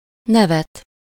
Ääntäminen
France (Paris): IPA: [œ̃ ʁiʁ]